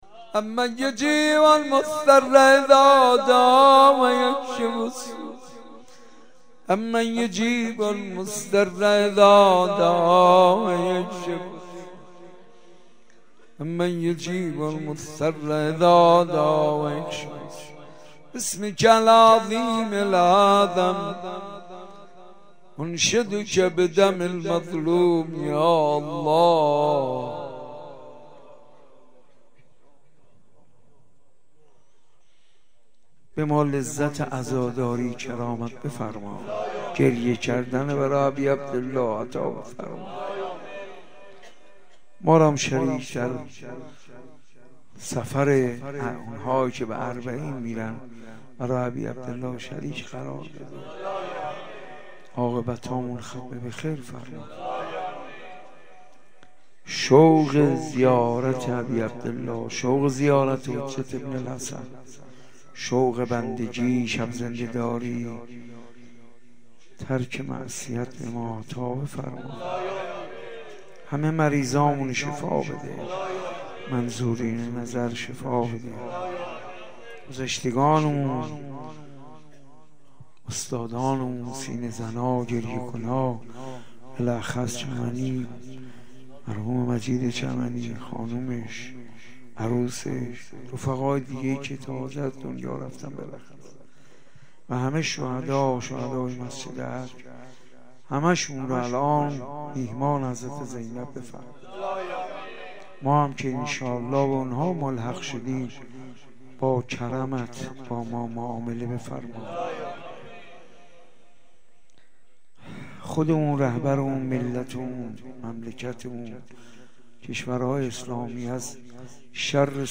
دعای آخر